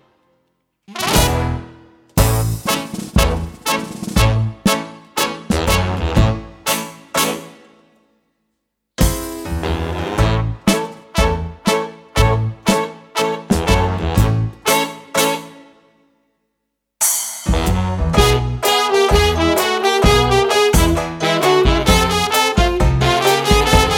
no Backing Vocals Pop (1970s) 4:38 Buy £1.50